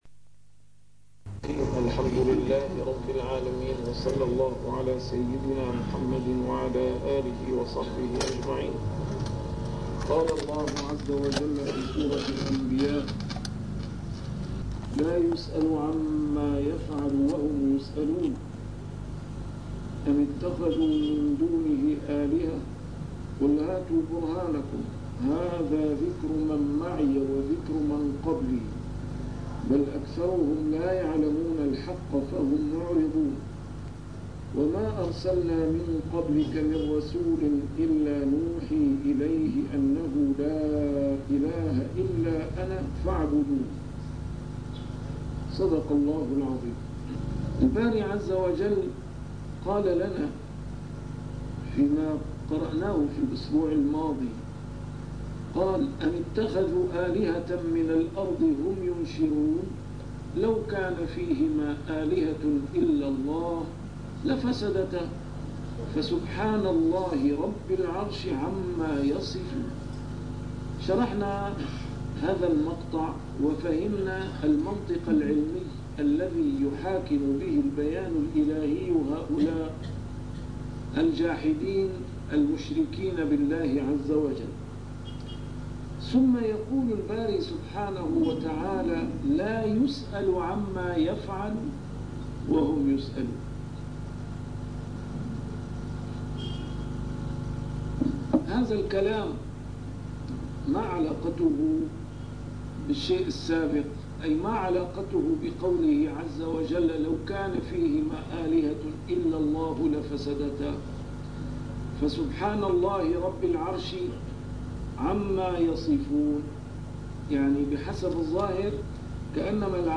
A MARTYR SCHOLAR: IMAM MUHAMMAD SAEED RAMADAN AL-BOUTI - الدروس العلمية - تفسير القرآن الكريم - تسجيل قديم - الدرس 86: الأنبياء 023-025